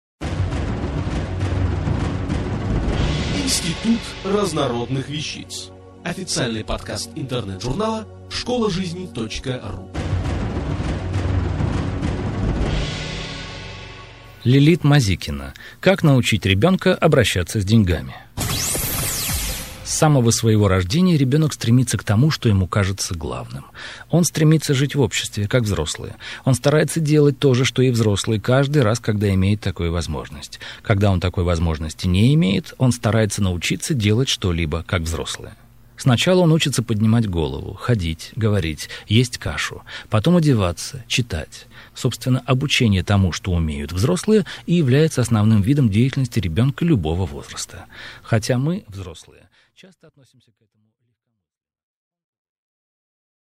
Аудиокнига Как научить ребёнка обращаться с деньгами?